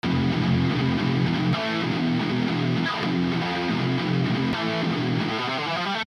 For the FL example and a quality benchmark, here is an exported mp3 of it stretched to 160bpm using their stretch mode.
I have these 4 bars of a heavy riff .wav files that make up a sample map that I wanted to add timestretching too which I'm doing via scripting;